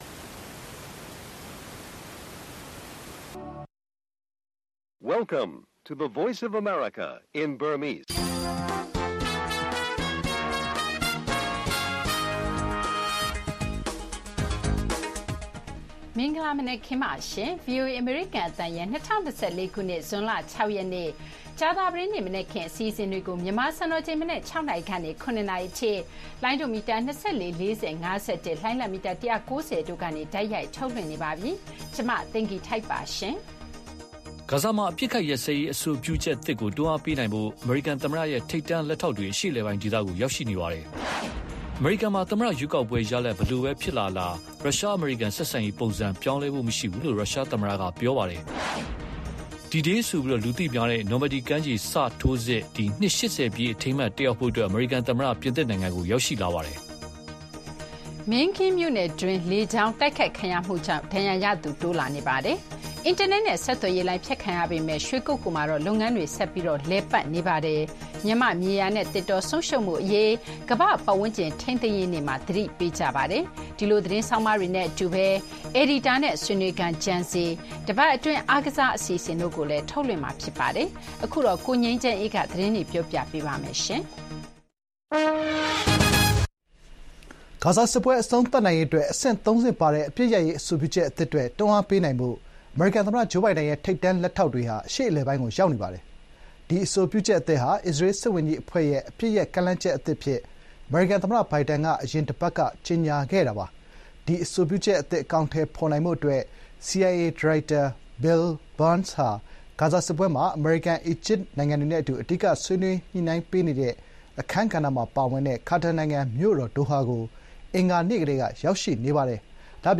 ဗွီအိုအေမြန်မာနံနက်ခင်း(ဇွန် ၆၊ ၂၀၂၄) D-Day နှစ်ပတ်လည်တက်ရောက်ဖို့ ကန်သမ္မတ ပြင်သစ်ရောက်ရှိ၊ ဂါဇာအပစ်ရပ်ရေးတွန်းအားပေးဖို့ ကန်အရာရှိများဒေသတွင်းရောက်ရှိ စတဲ့သတင်းတွေနဲ့ အပတ်စဉ်ကဏ္ဍတွေမှာ အယ်ဒီတာနဲ့ဆွေးနွေးခန်း၊ Gen Z နဲ့ အားကစားအစီအစဉ်တွေ ထုတ်လွှင့်ပေးပါမယ်။